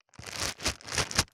627コンビニ袋,ゴミ袋,スーパーの袋,袋,買い出しの音,ゴミ出しの音,袋を運ぶ音,
効果音